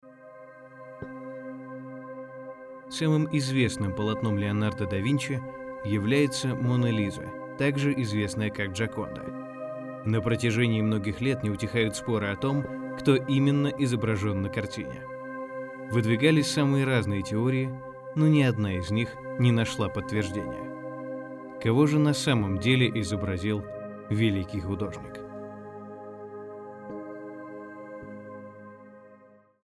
Микрофон AKG P220, звуковая карта Focusrite scarlett solo, акустическая кабина